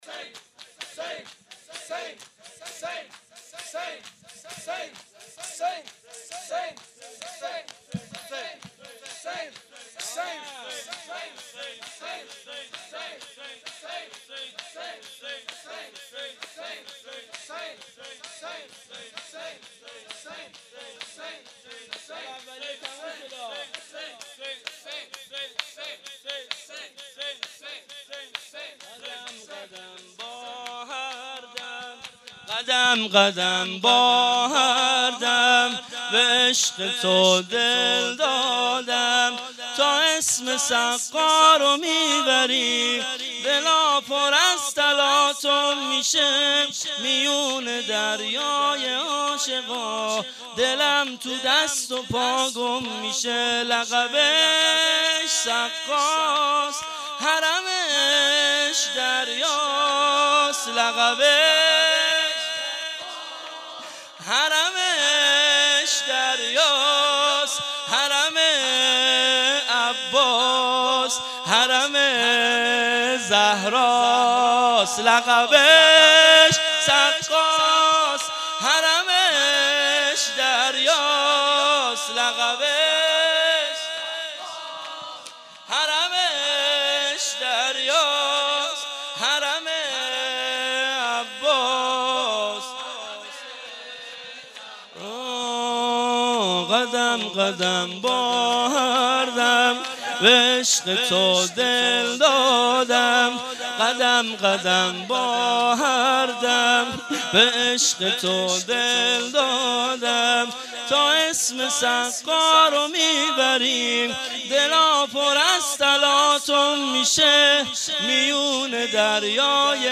شب اول- شور